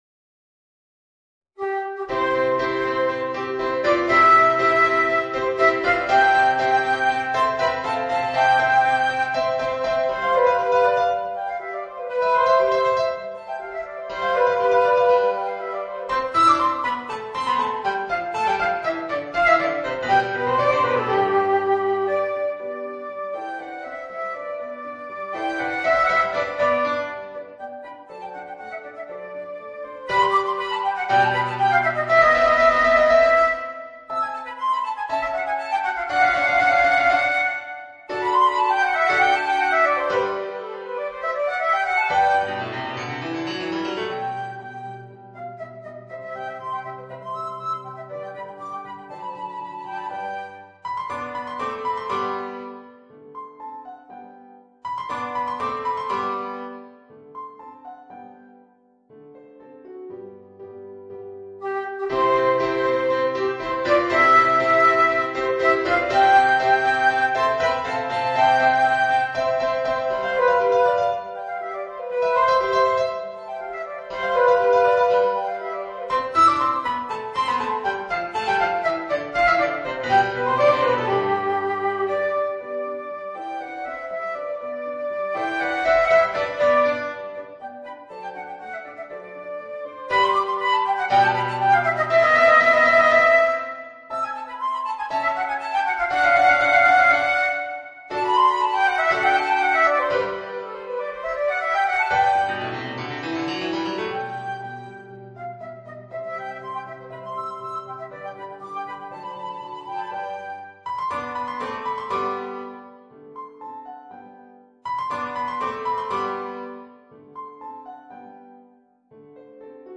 Voicing: Flute and Piano